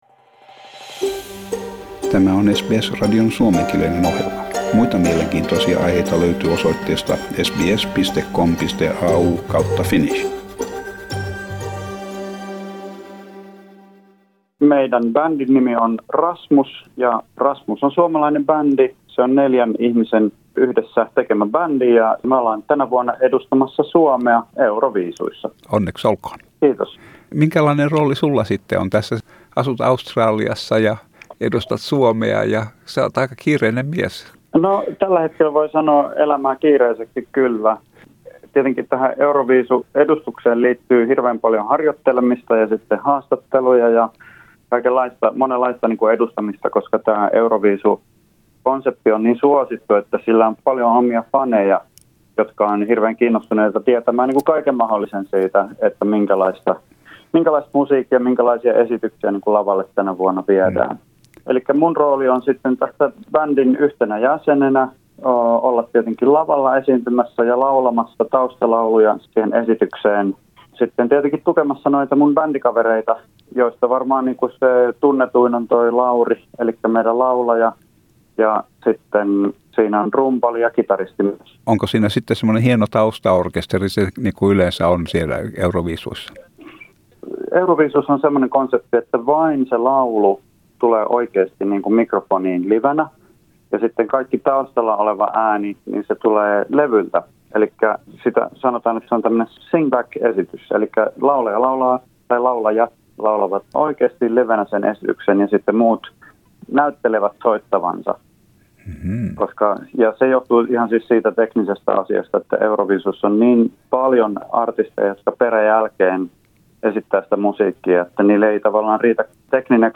Eero is also a musician, he plays bass and is a founding member of The Rasmus The Rasmus is now in Italy, preparing for the Eurovision Song Contest representing Finland in the contest. In this interview we talk about the feel of the contest and the entries, Eero begins by telling a little about The Rasmus Share